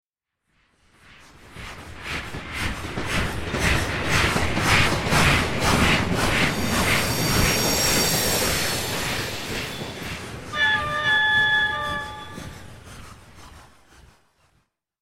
دانلود آهنگ قطار 5 از افکت صوتی حمل و نقل
جلوه های صوتی
دانلود صدای قطار 5 از ساعد نیوز با لینک مستقیم و کیفیت بالا